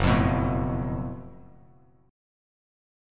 GbmM11 chord